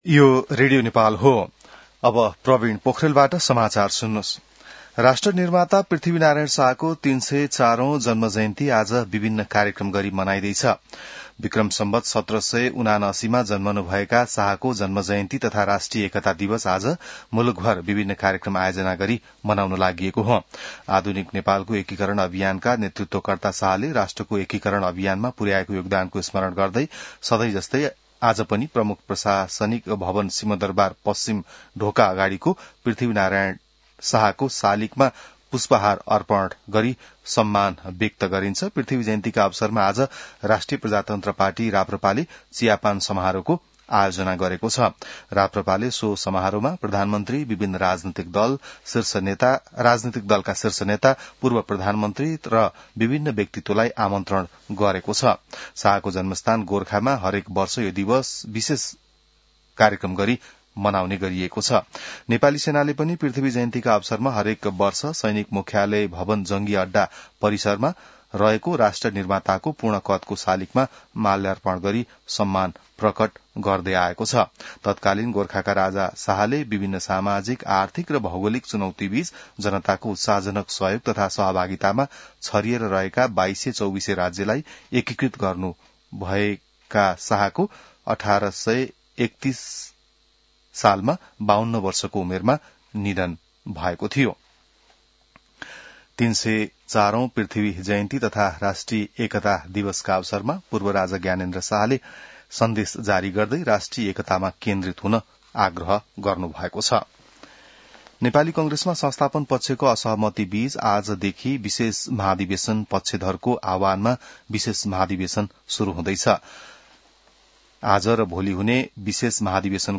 बिहान ६ बजेको नेपाली समाचार : २७ पुष , २०८२